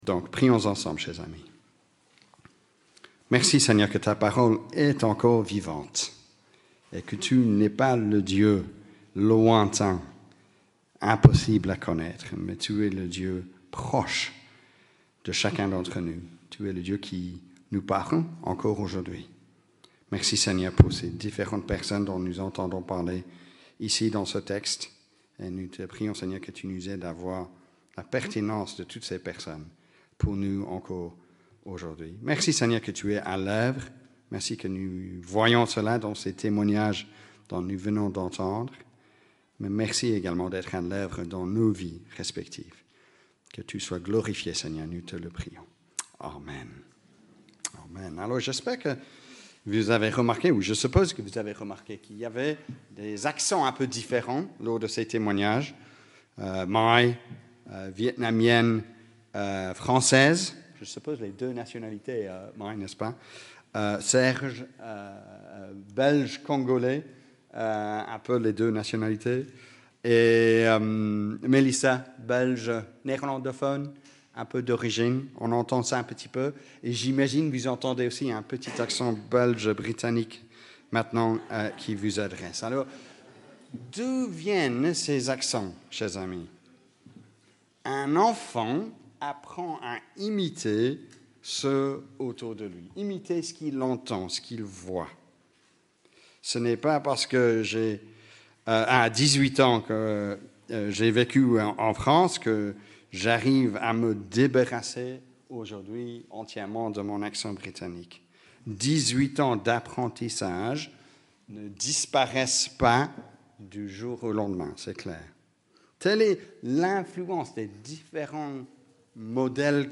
Prédication-11-06.mp3